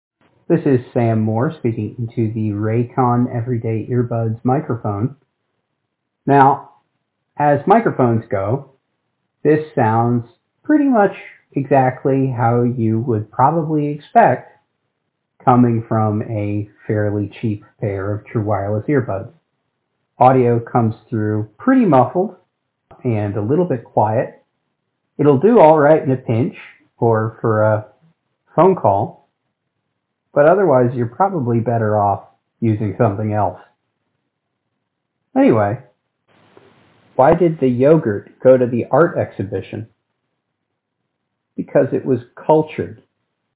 Raycon-Everyday-Earbuds-mic-sample.mp3